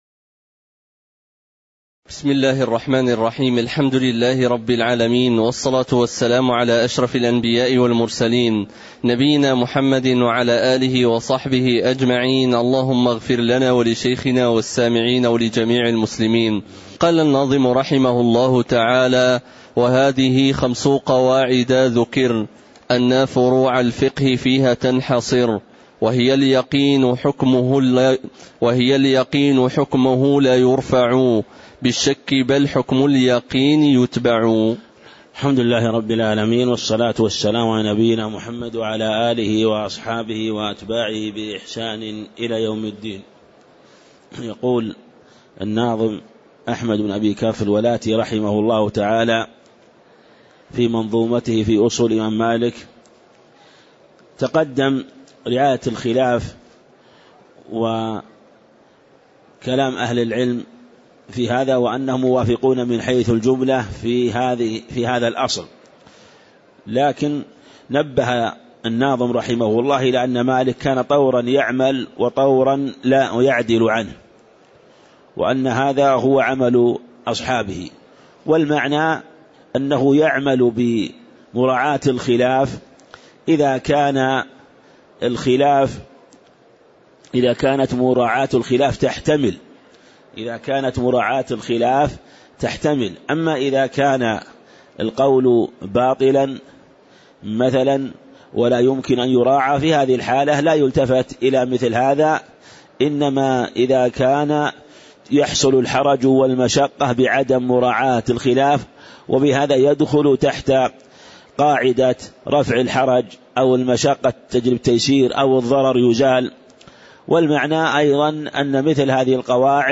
تاريخ النشر ٨ جمادى الآخرة ١٤٣٧ هـ المكان: المسجد النبوي الشيخ